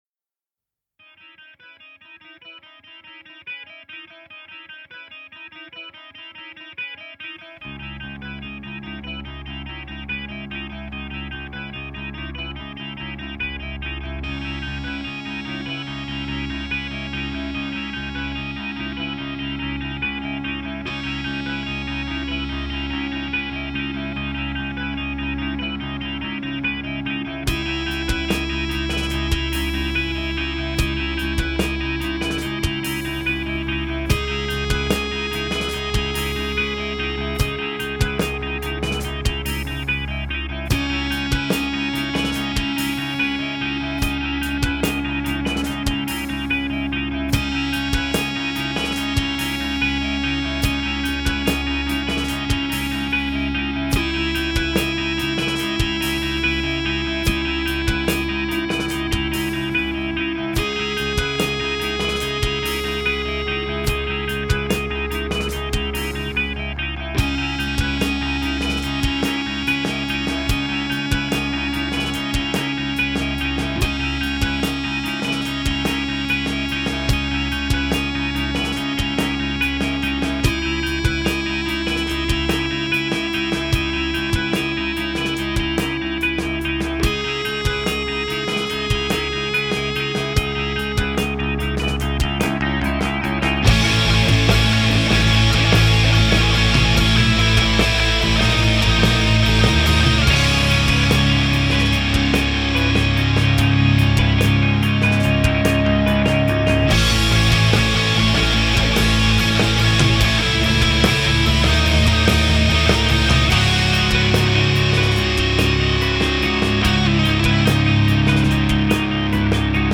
Gatunek: Progresywny/Alternatywny Rock
Gitara prowadząca
Perkusja
Gitara basowa